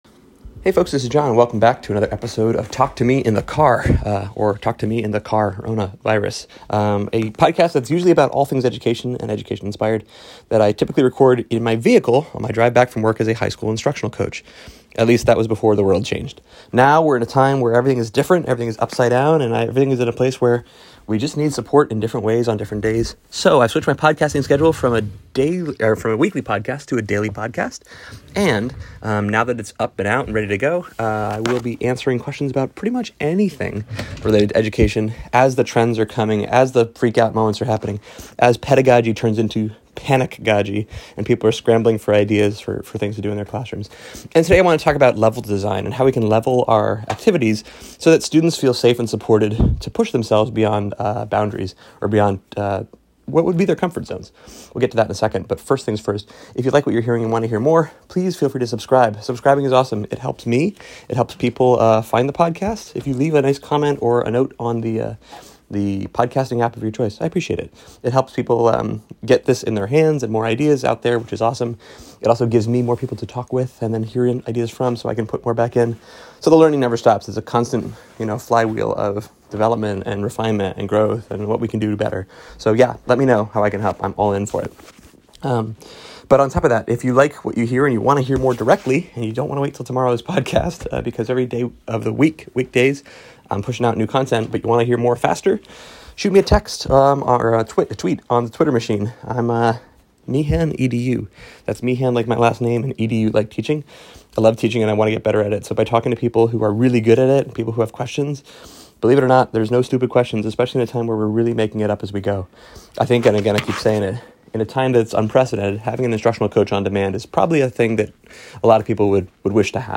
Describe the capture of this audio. Recorded in bumper-to-bumper traffic